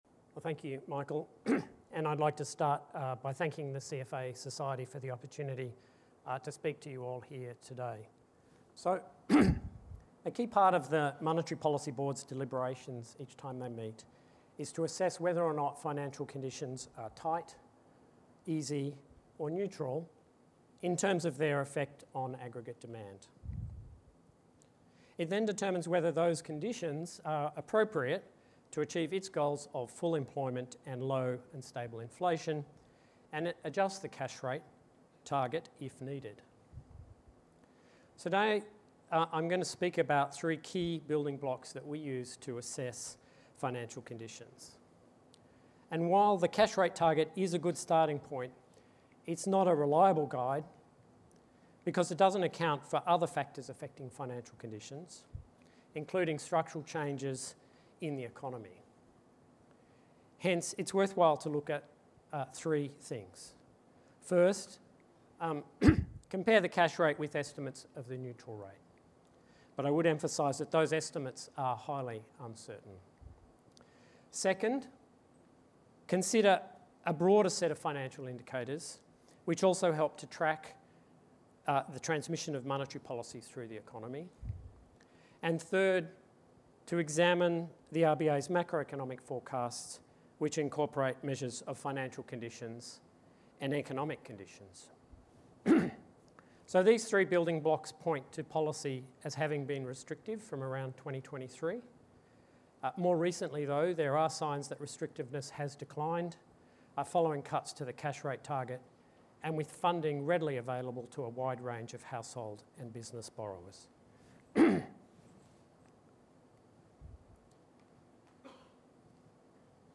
Speech delivered by Christopher Kent, Assistant Governor (Financial Markets), to CFA Society Australia, Sydney
Address to CFA Society Australia Sydney – 16 October 2025